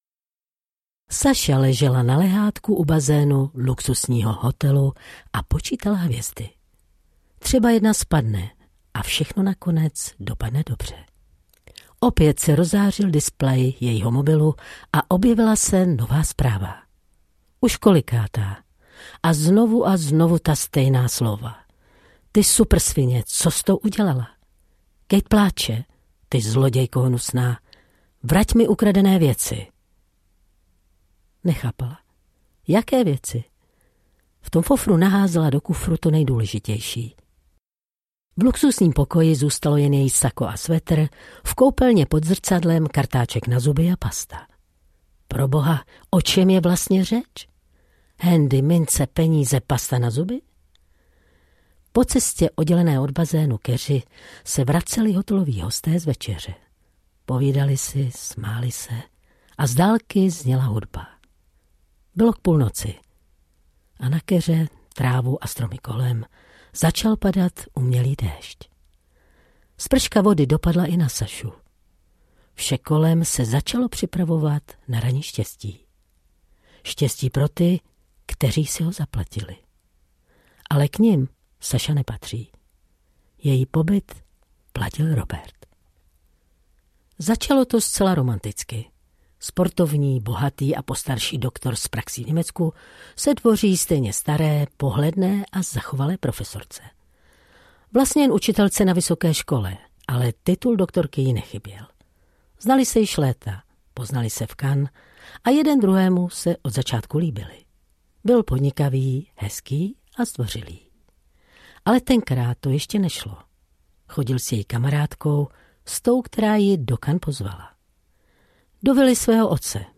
Hra o holčičku audiokniha
Ukázka z knihy